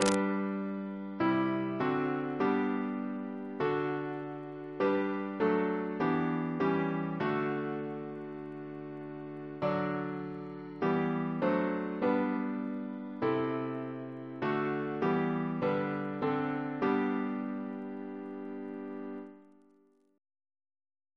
Double chant in G Composer: John Lemon (1754-1814) Reference psalters: ACB: 314; PP/SNCB: 28; RSCM: 88